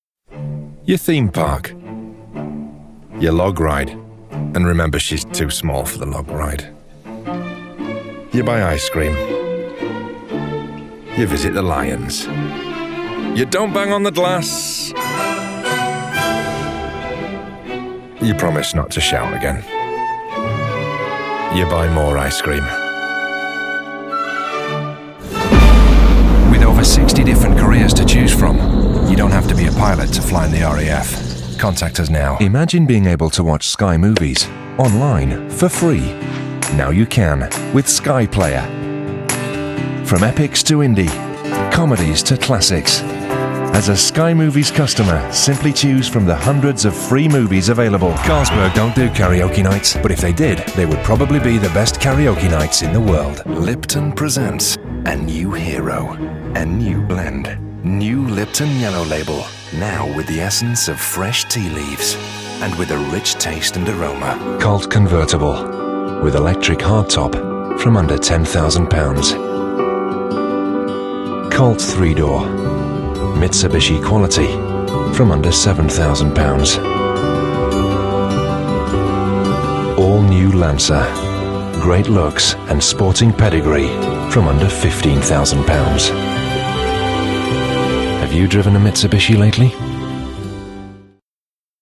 Commercial Reel
Northern, Straight
Smooth, Warm, Friendly, Commercial